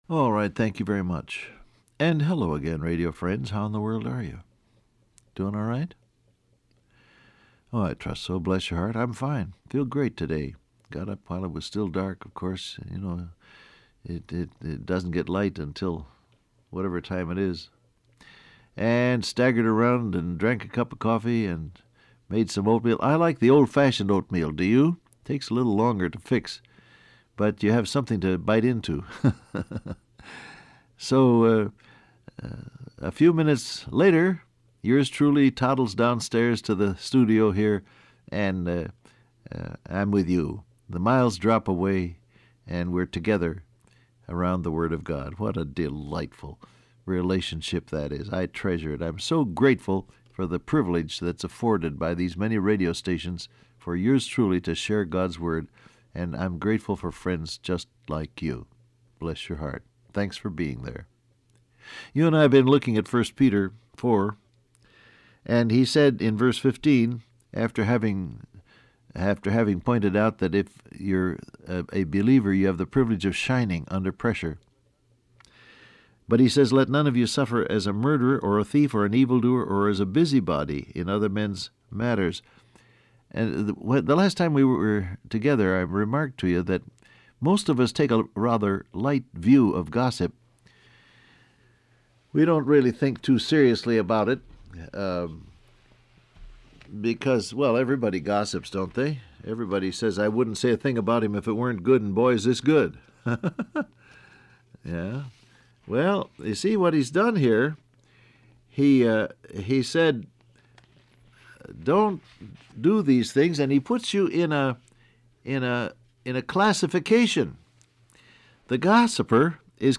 Download Audio Print Broadcast #7170 Scripture: 1 Peter 4:15 , Romans 8:28 Topics: Opposition , Testimony , Glory , Words , Suffering , Gossip Transcript Facebook Twitter WhatsApp Alright, thank you very much.